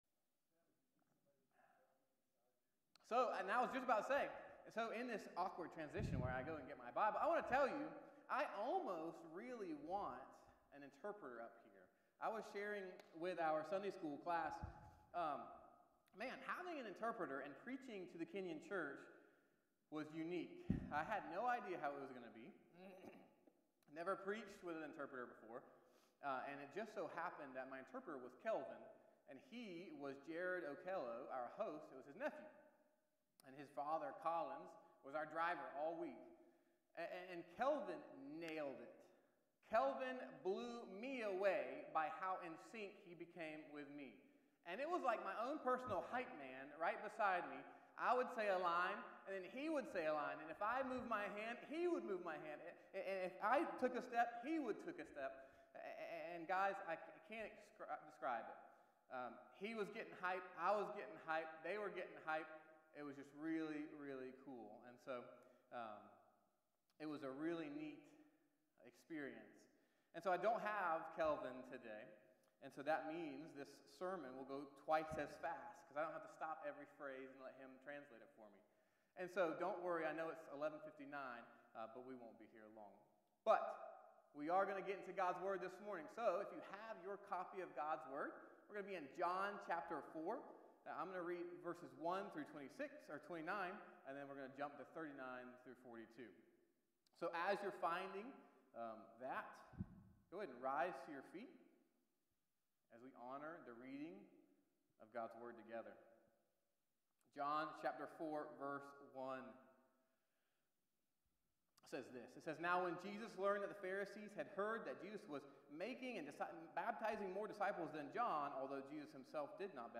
Morning Worship - 11am